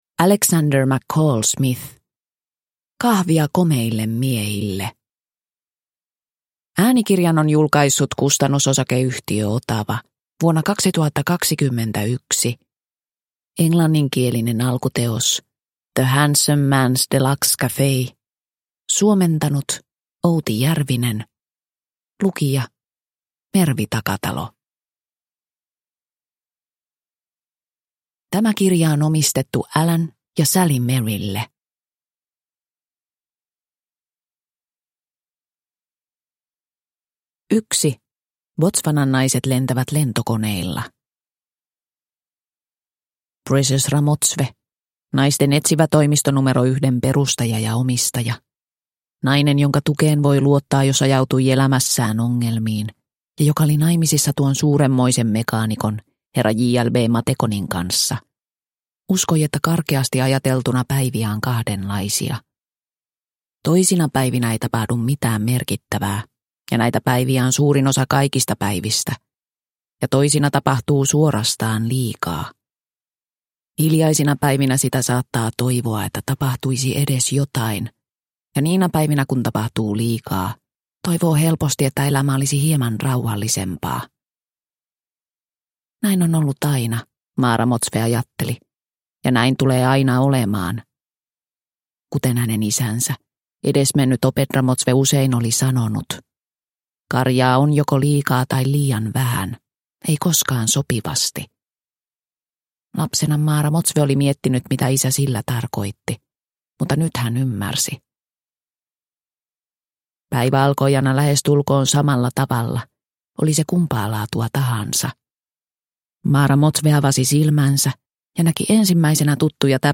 Kahvia komeille miehille – Ljudbok – Laddas ner